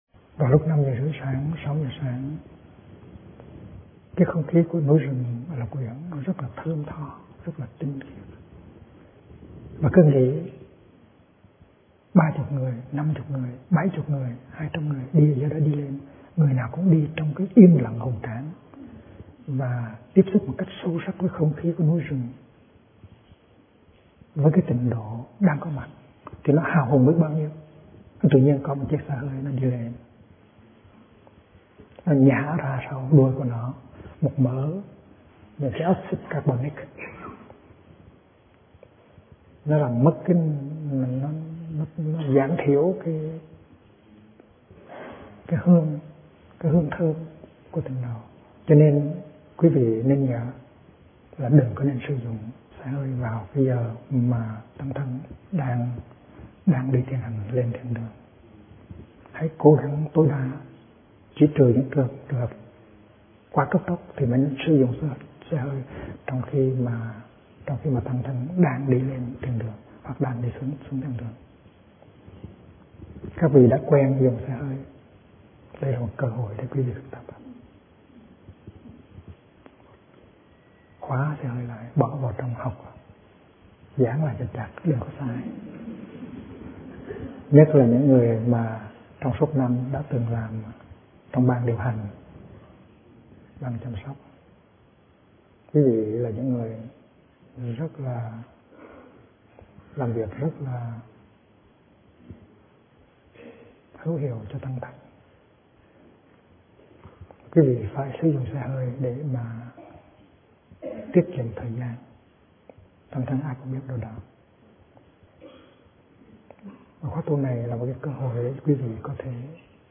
Kinh Giảng Trở Về Thực Tại - Thích Nhất Hạnh